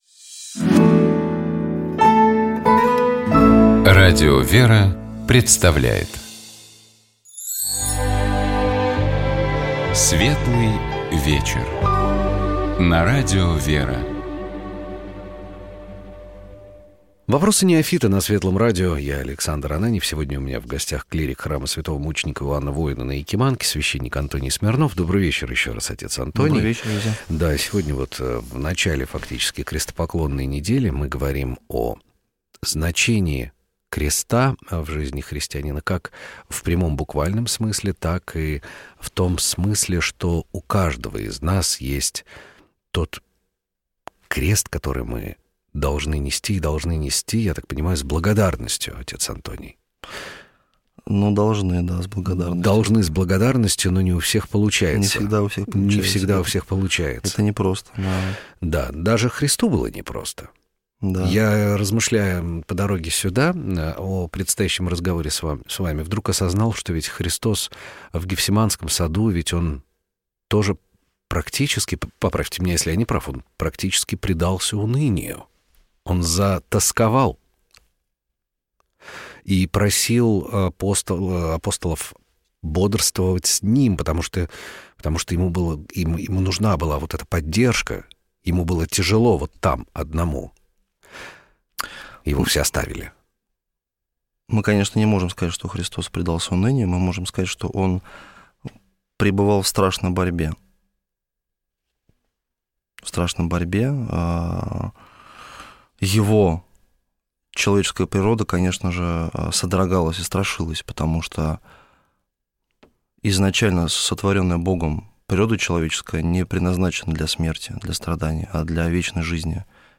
Эфирная студия радио «Вера» находится в одном из красивейших мест Москвы, как мне кажется.